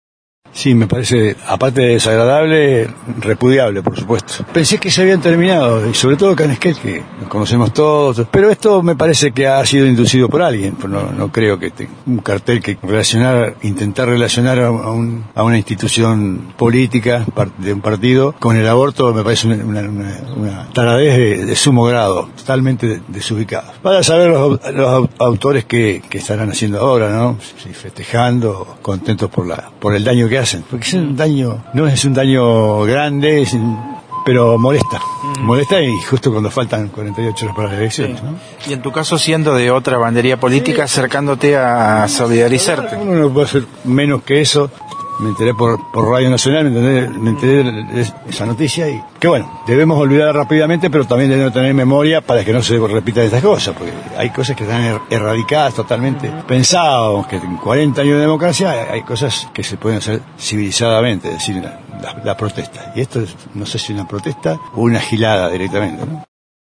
Noticias de Esquel estuvo en el local del FV donde dialogamos con el ex intendente Daniel Diaz que se acercó a solidarizarse y repudió este suceso.